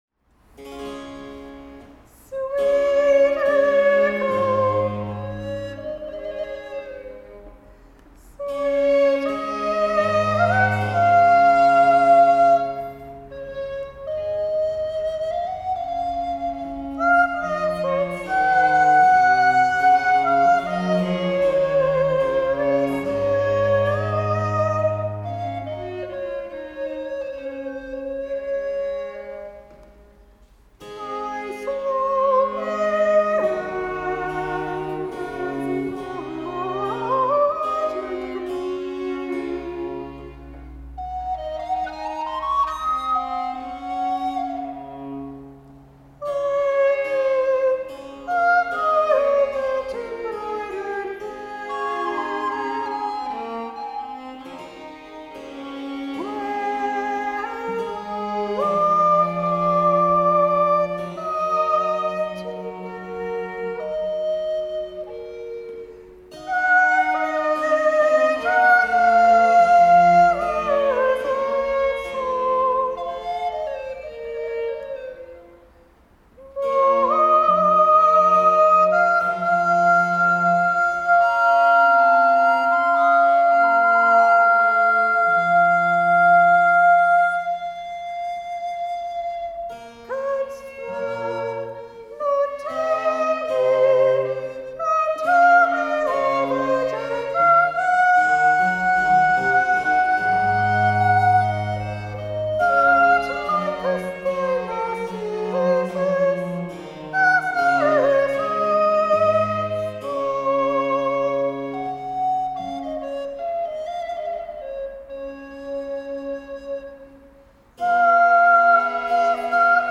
The third, “Sweet Echo, Sweetest Nymph,” a soprano aria with flute obbligato, comes soon after the tenor song and provides a ravishing contrast to its earthy gaiety.
recorder, in Adelaide, South Australia